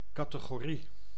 Ääntäminen
US : IPA : [ˈkæt.əˌɡɔɹ.i] US : IPA : /ˈkæɾəˌɡɒɹi/